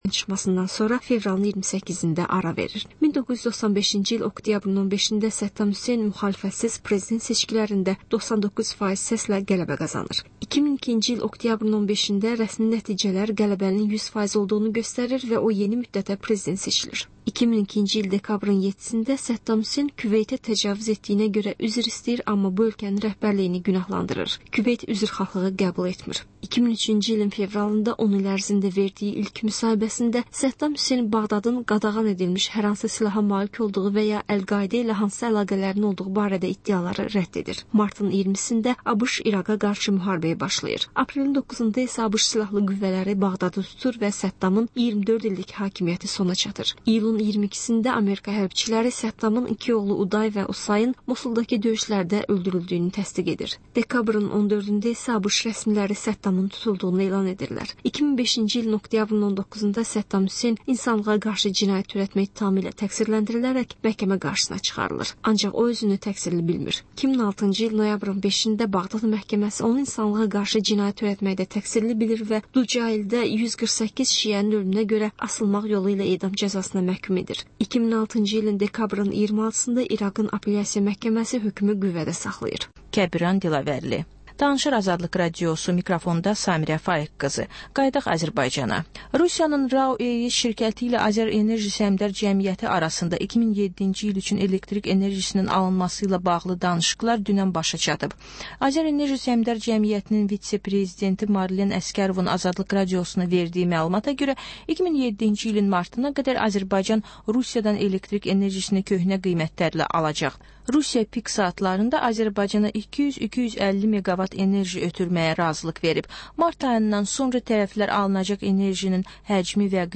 Azərbaycan, Gürcüstan ve Ermənistandan reportajlar, müzakirələr